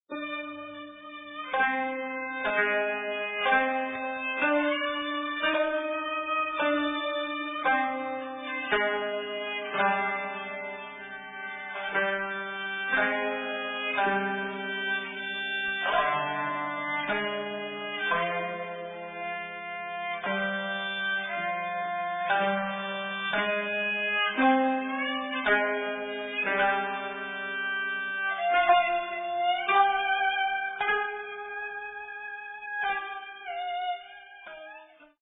2 Kotos